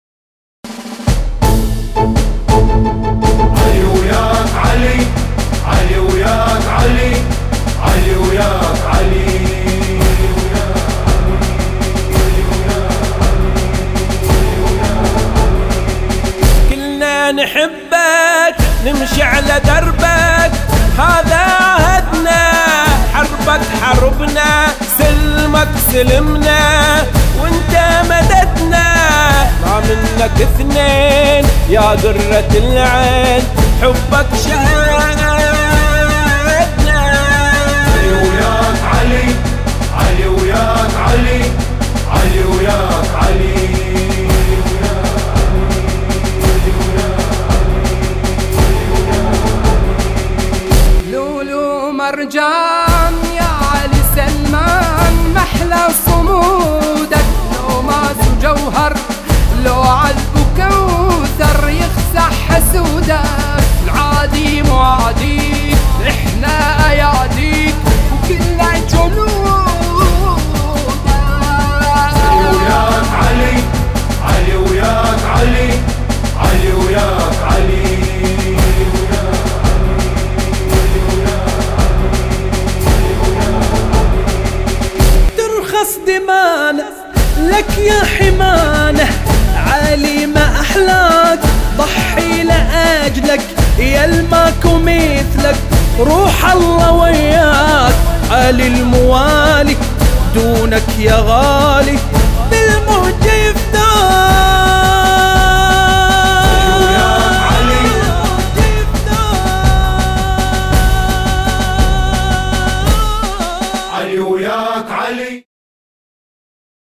أناشيد بحرينية
اناشيد وطنية